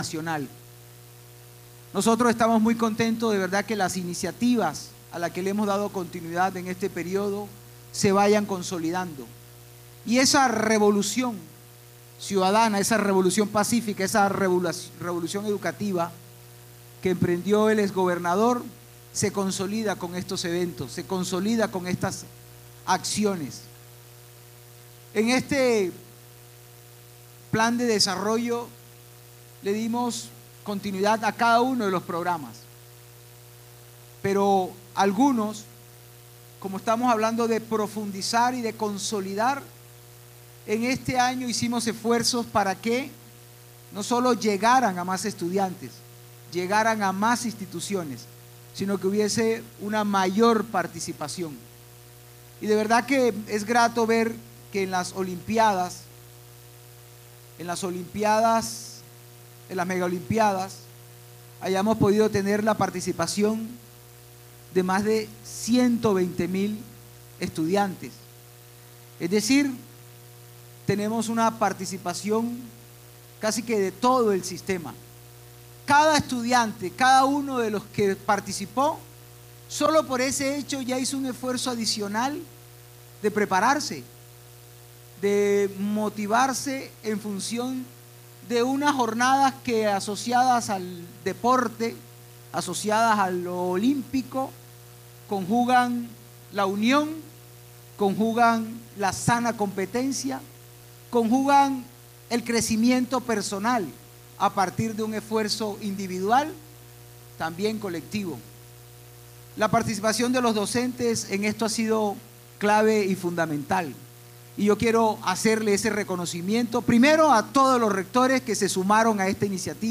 AUDIO-DEL-GOBERNADOR-MARTINEZ-mp3cut.net_.mp3